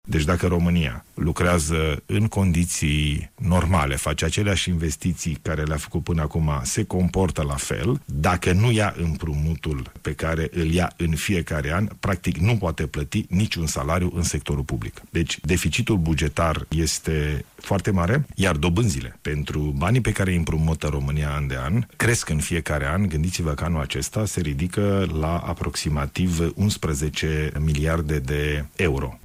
Într-un amplu interviu la RRA, Premierul a subliniat că reducerile de costuri în sectorul public sunt necesare, întrucât deficitul estimat pentru finalul acestui an însumează aproape 150 de milioane de lei.